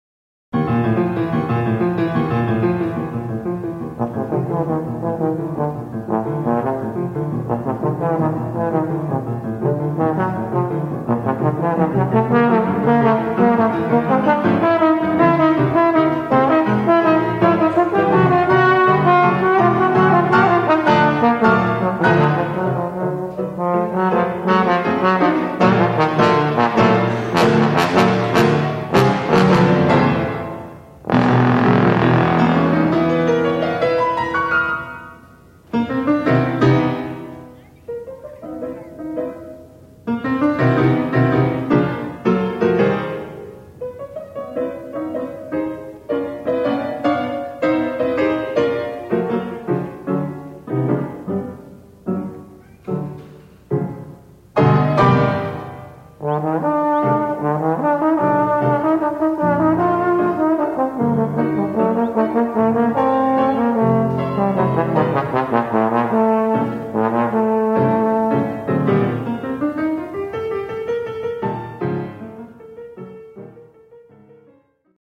bass trombone solo recording
Live Performances in Concert
piano.